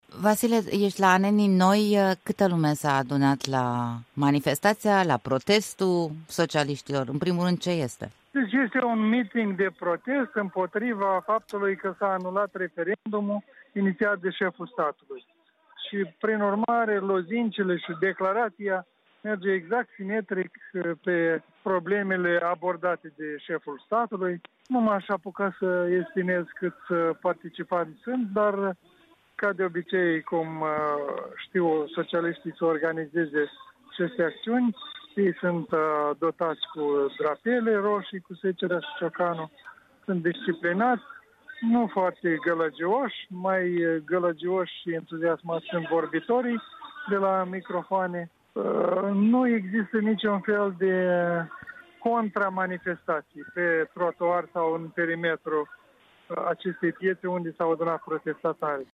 Corespondenții Europei Libere transmit de la Bălți, Anenii Noi și Cahul.